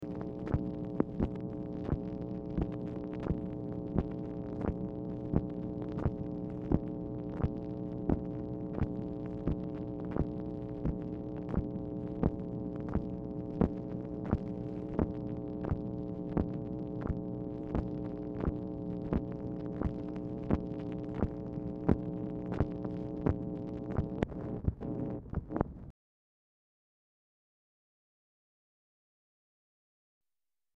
Telephone conversation # 5913, sound recording, MACHINE NOISE, 10/17/1964, time unknown | Discover LBJ
Format Dictation belt